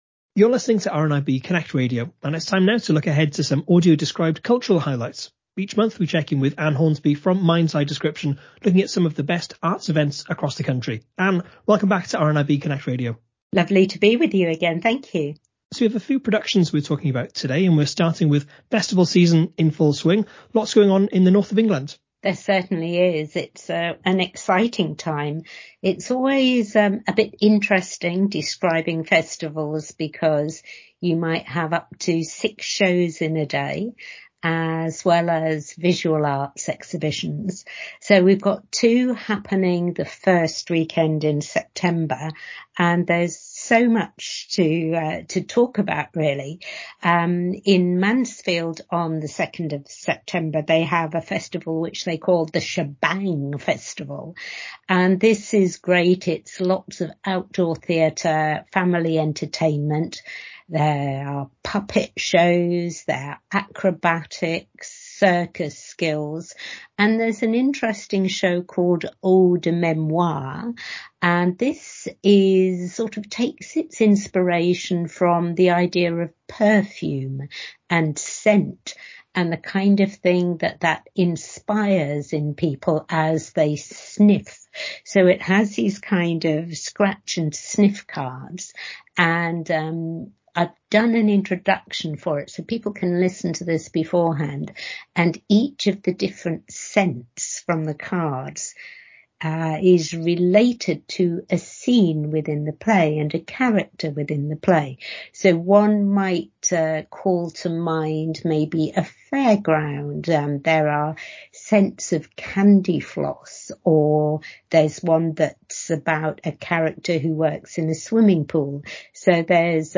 Some Audio Described Highlights From MindsEye Description